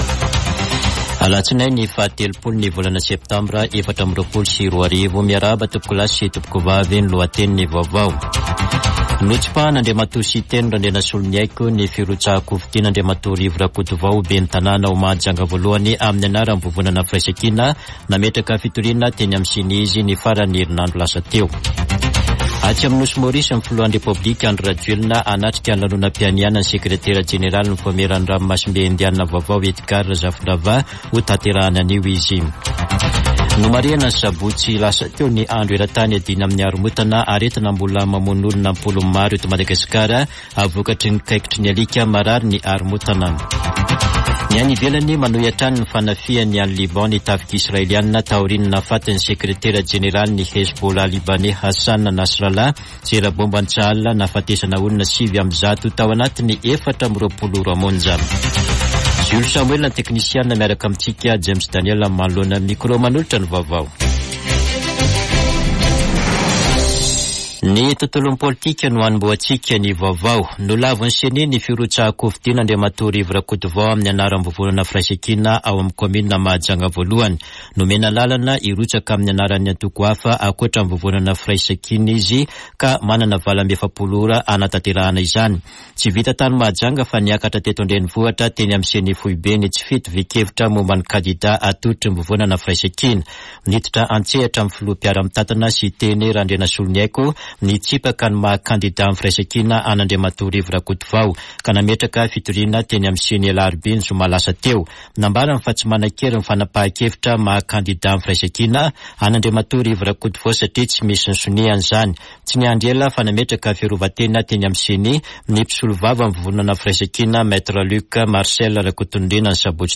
[Vaovao maraina] Alatsiniany 30 septambra 2024